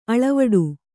♪ aḷavaḍu